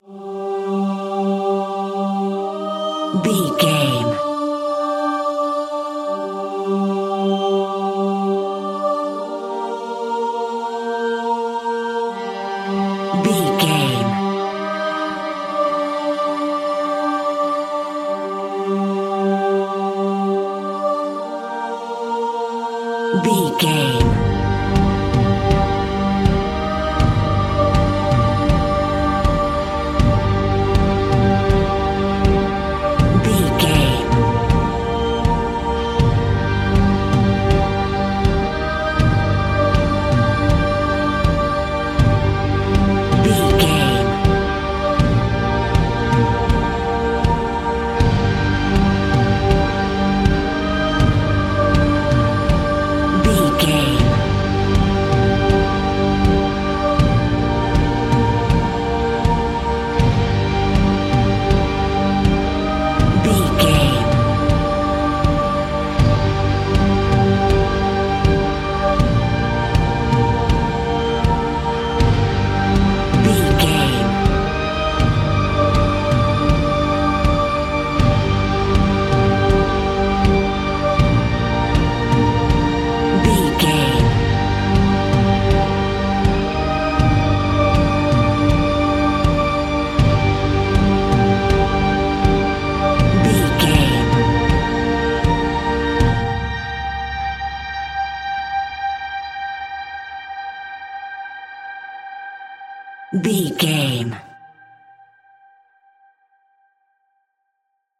Epic / Action
Aeolian/Minor
Slow